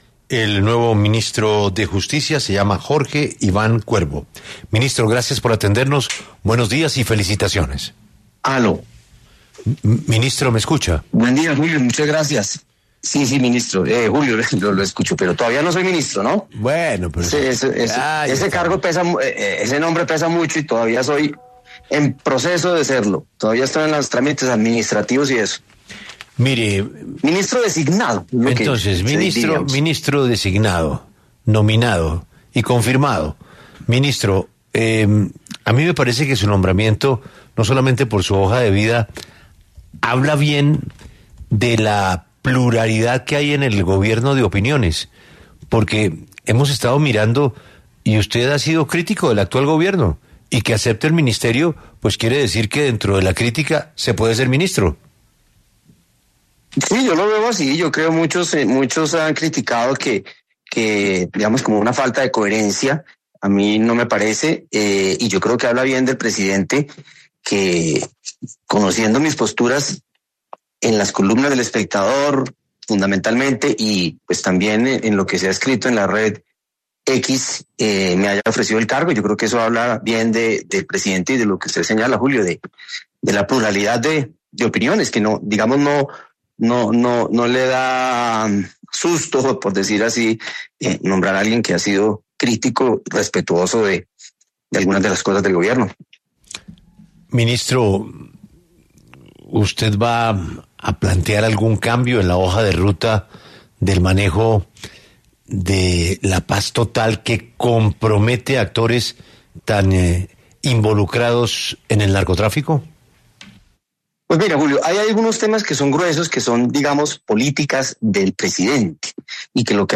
Primera entrevista ministro designado Jorge Iván Cuervo